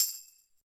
soft-slidertick.ogg